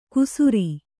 ♪ kusuri